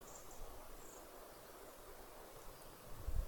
Hjūma ķauķītis, Phylloscopus humei
StatussDzirdēta balss, saucieni
Piezīmes/Ieklausoties apkārtnē dzirdēti trīs aizdomīgi saucieni, pirmais iespaids bija sila cīrulis, uzreiz skaidrs ka kaut kas īsti nav, sāku ierakstīt, paralēli ierakstot aizdomas par PHYHUM iespējams divi no aptuveni 8 saucienu sērijas (ar 15sek puzēm starp saucieniem) būs ierakstīti. pēc apklusa uzslēdzu PHYHUM saucienus no telefona, tā arī vairāk neatbildēja.
Saucieni no krūmiem vai priedēm tuvāk jūrai.